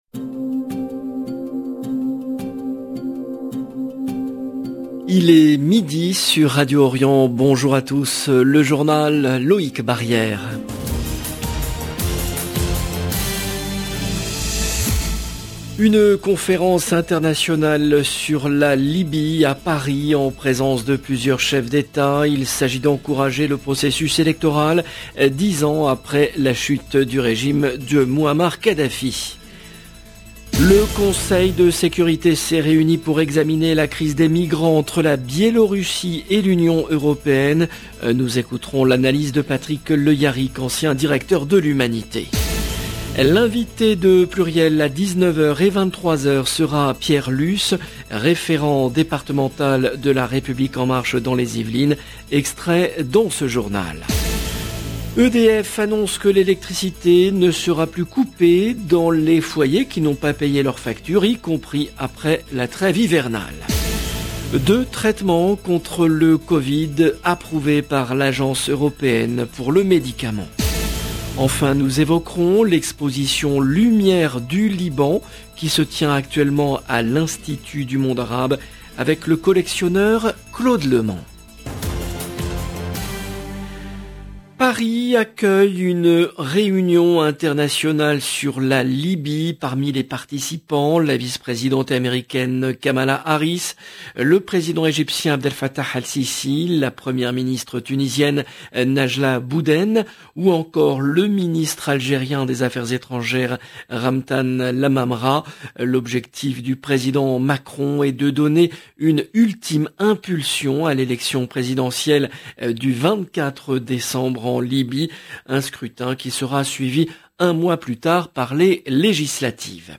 LE JOURNAL DE MIDI EN LANGUE FRANCAISE DU 12/11/21